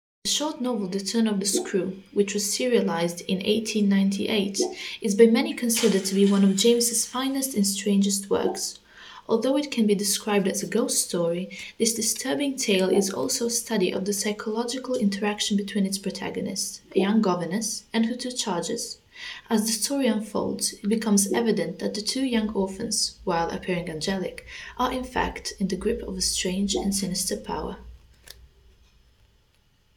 Language Reels
English level: native